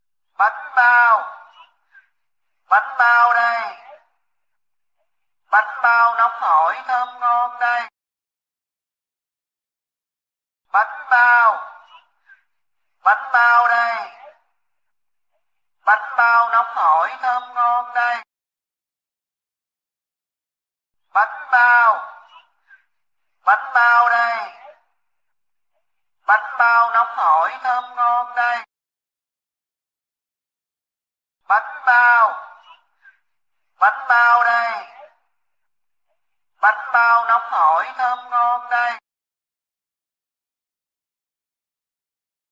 Tiếng rao Bánh Bao
Thể loại: Tiếng con người
tieng-rao-banh-bao-www_tiengdong_com.mp3